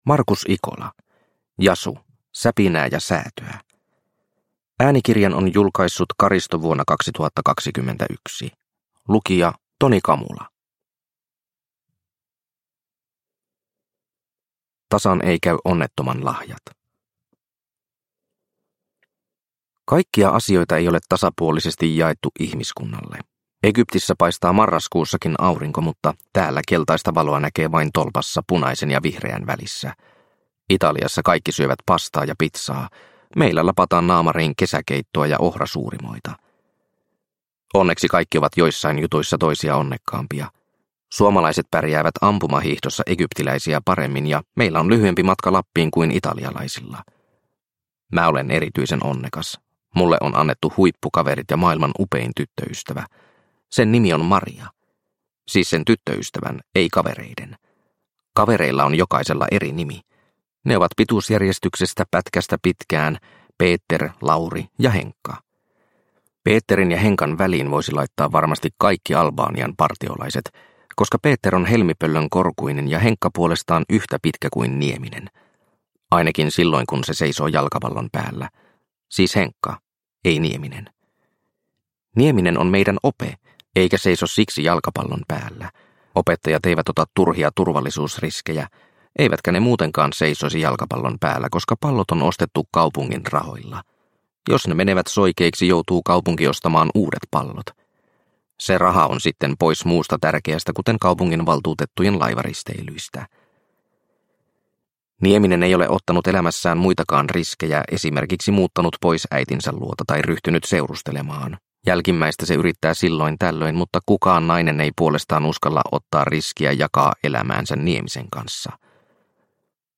Jasu - Säpinää ja säätöä – Ljudbok – Laddas ner